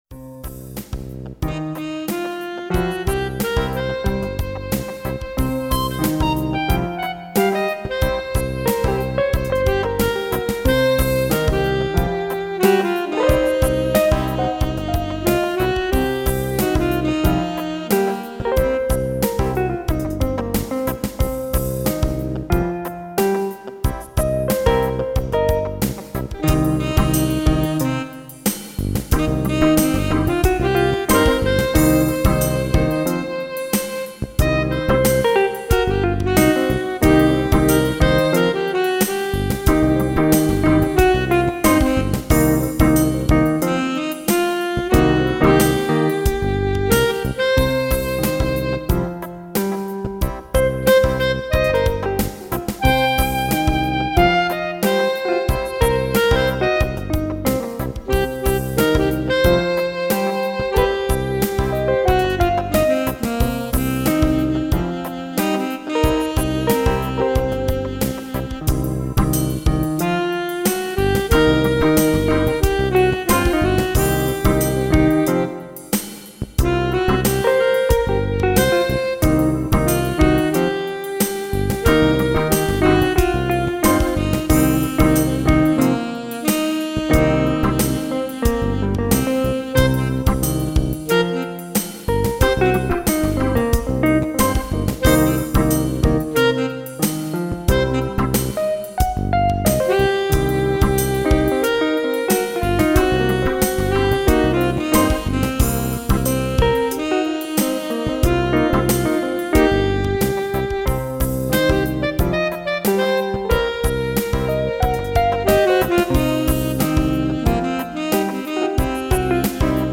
Tempo: 47 bpm / Date: 31.05.2017
Jazz /Creative Commons License 4.0 / noncommercial use free